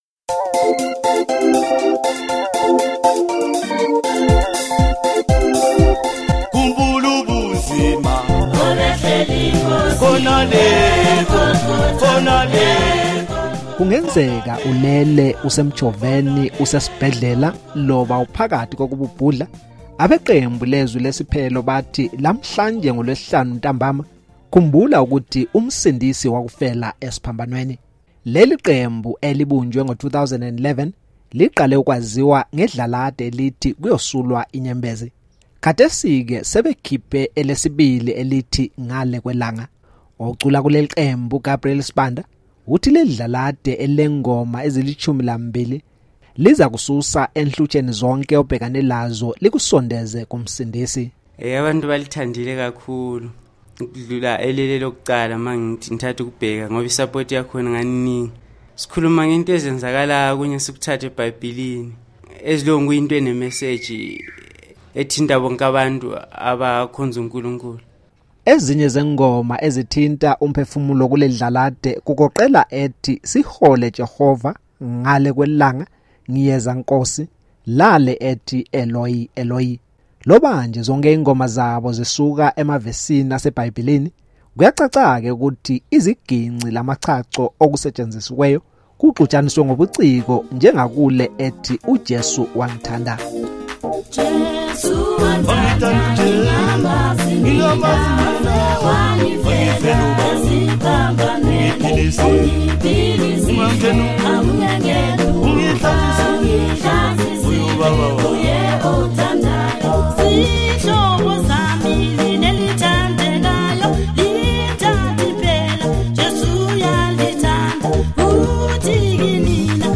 Lobanje zonke ingoma zabo zisuka emavesini asebhayibhilini, kuyacacake ukuthi iziginci lamachacho okusetshenzisiweyo kuxuxaniswe ngobuciko.